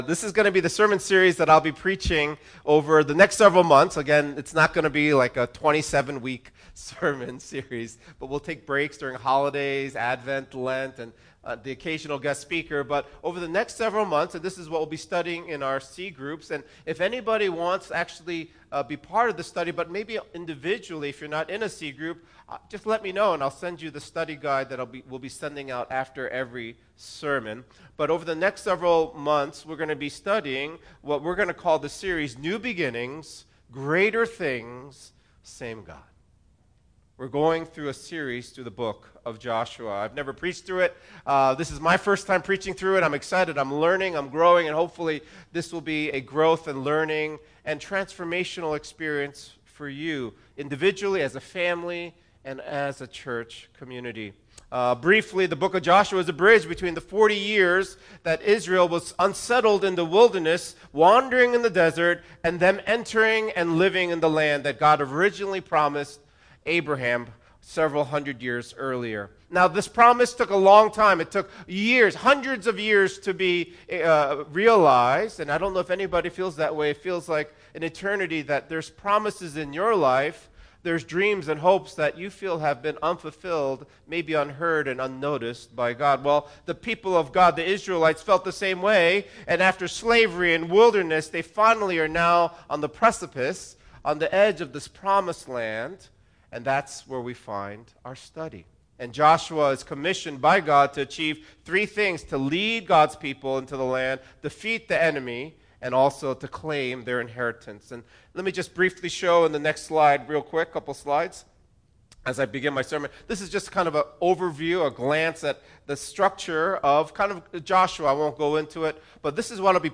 251012-Sermon.mp3